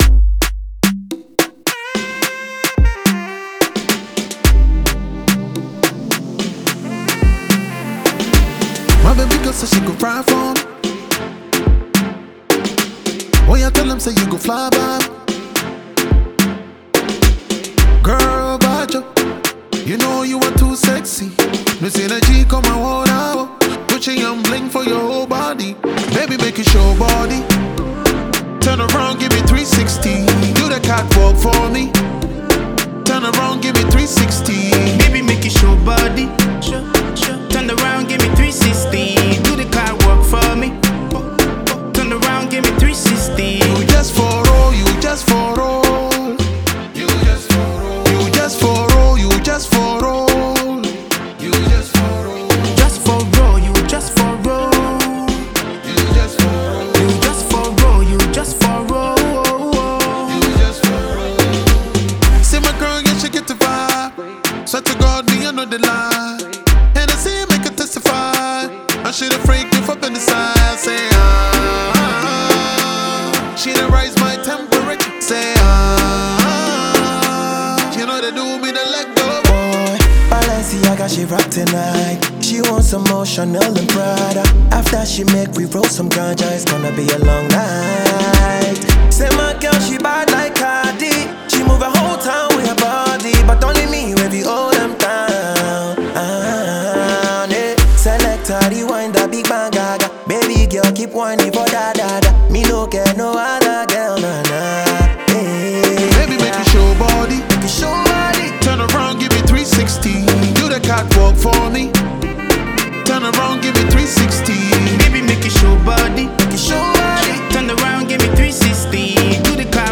New tune from Ghanaian rap legend